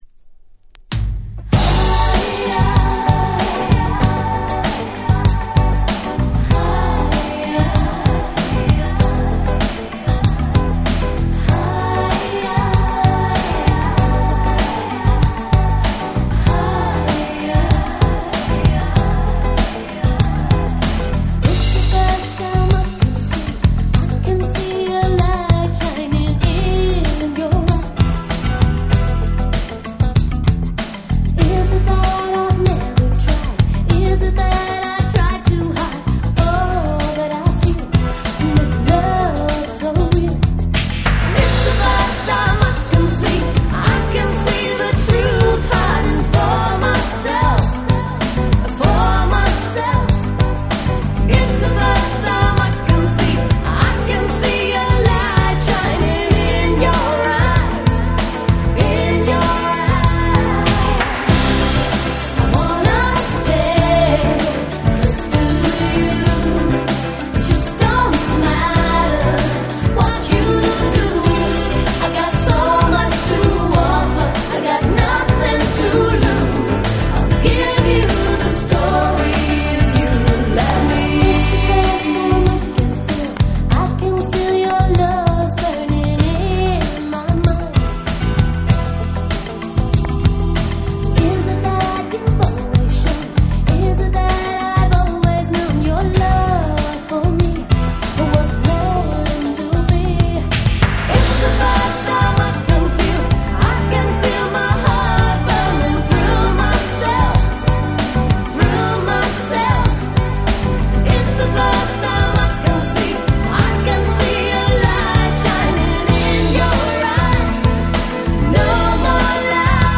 90' Super Nice UK R&B / UK Soul !!
超爽やか！！超切ない！！超最高！！